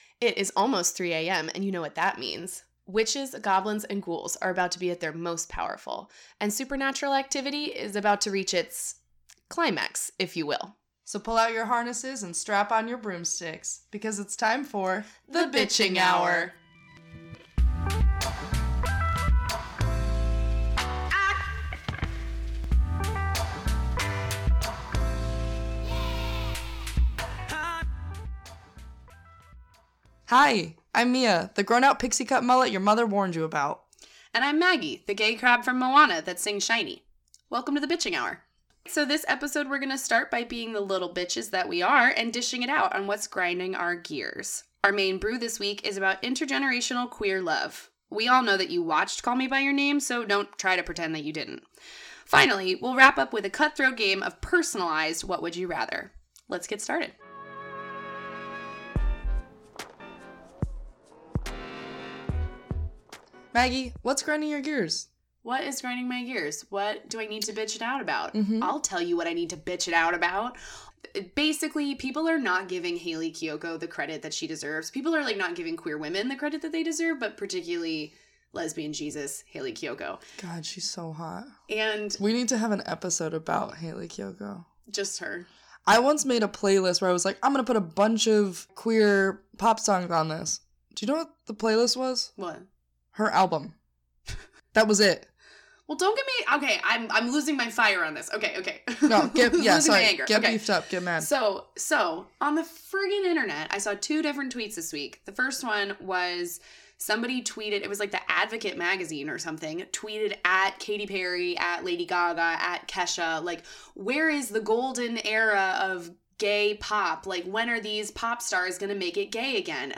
Transition music